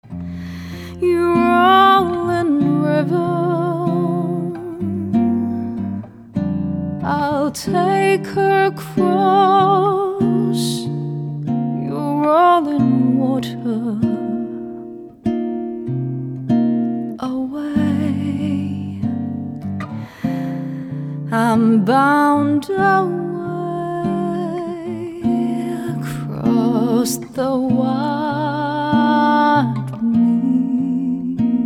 發燒女聲、發燒天碟